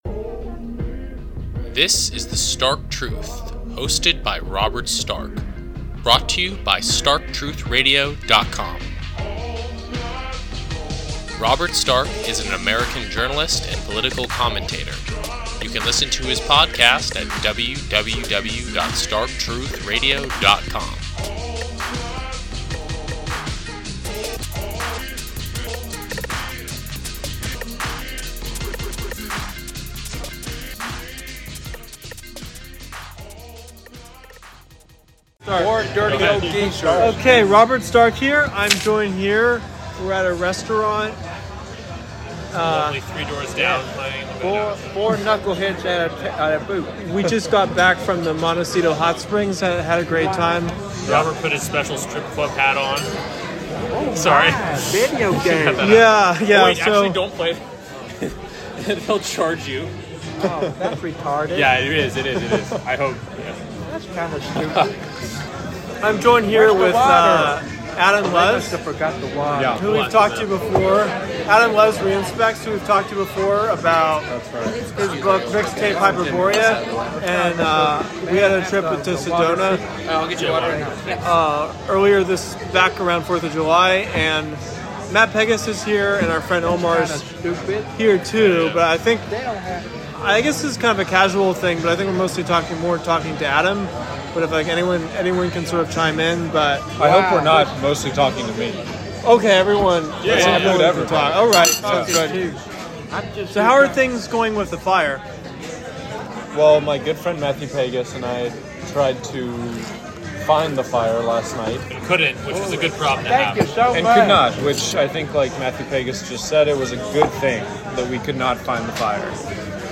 (Please excuse the background noise and interruptions as we recorded while eating dinner in a crowded restaurant, after a long day at the Hot Spring)